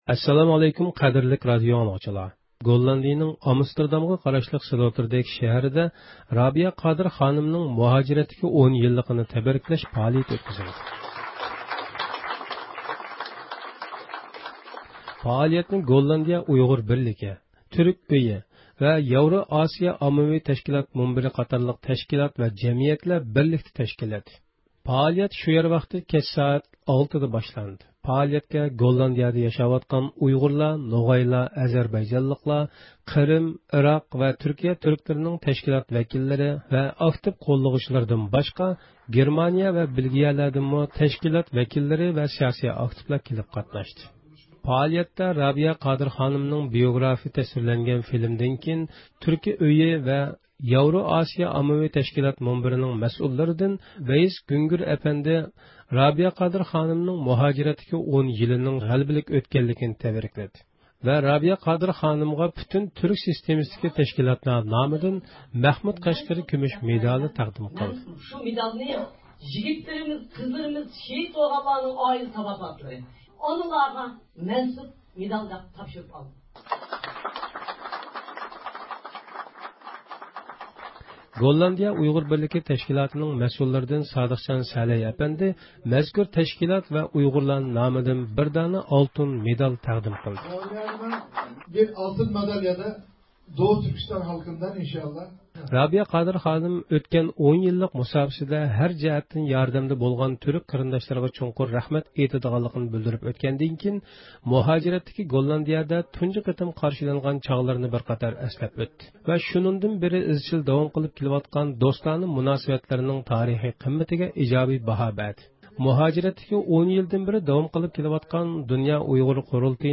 12-دېكابىر گوللاندىيەنىڭ ئامستېردامغا قاراشلىق سلوتېردەيك شەھىرىدە، رابىيە قادىر خانىمنىڭ مۇھاجىرەتتىكى ئون يىللىقىنى تەبرىكلەش پائالىيىتى ئۆتكۈزۈلدى.